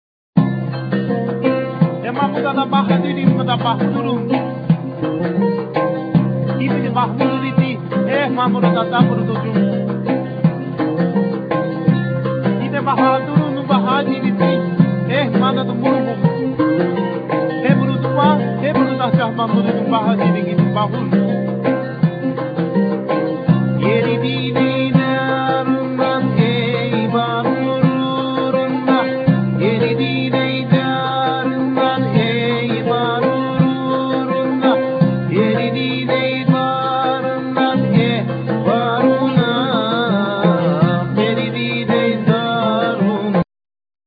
Vocal,Sazabo,Duduk,Percussion
Oud,Cumbus,Sazbus,Keyboards